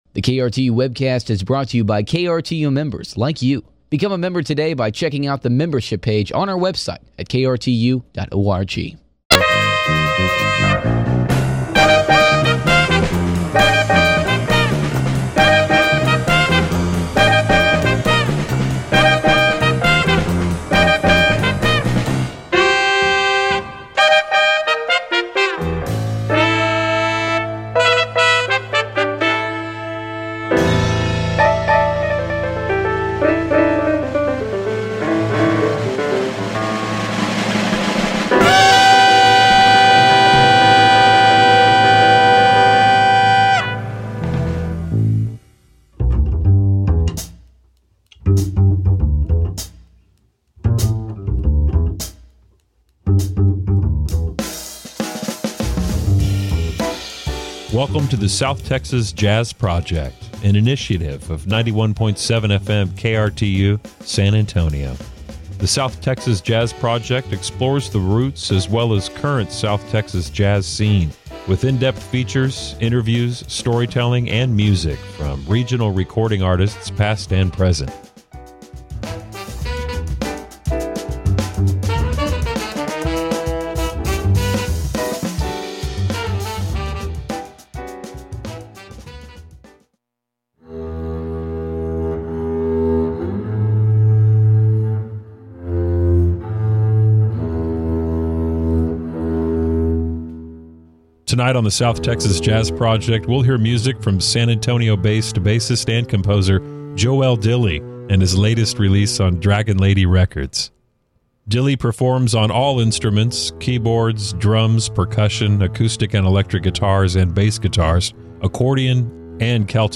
Listen to the story of Wall in the Desert on KRTU 91.7 FM